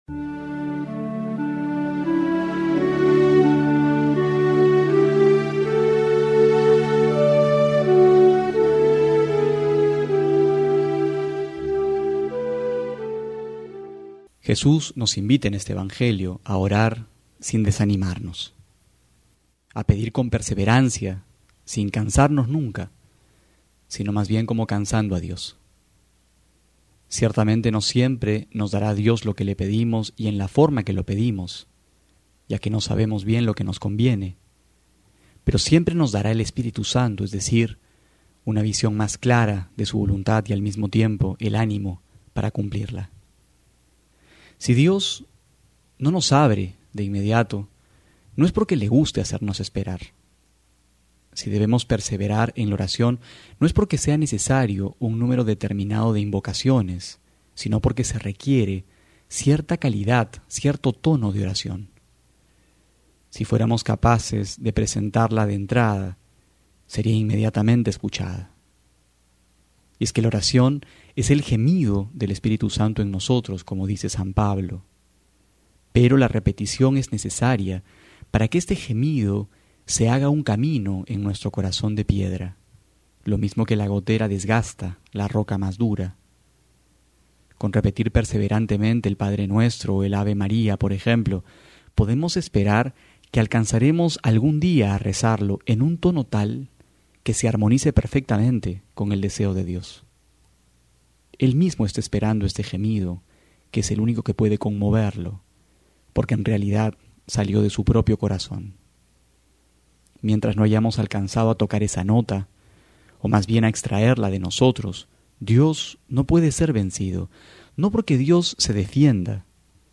Homilía para hoy:
noviembre18homilia.mp3